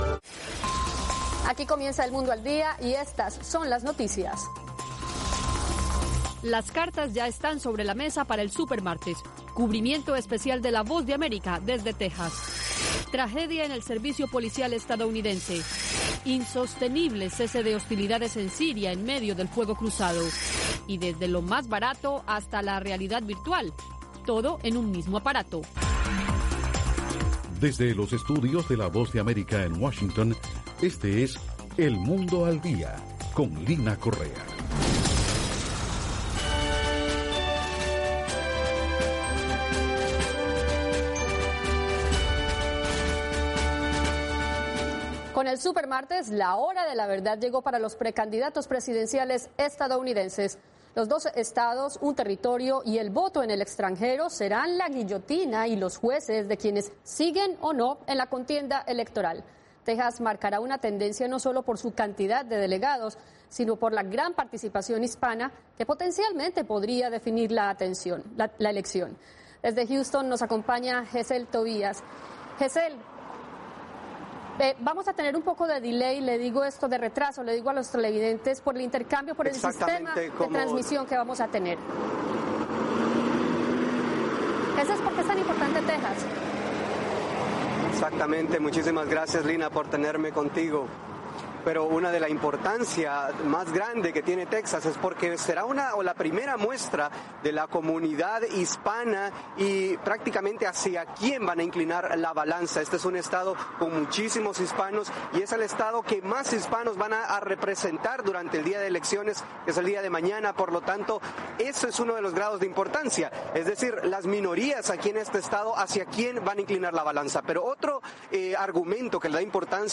Simultaneo en radio.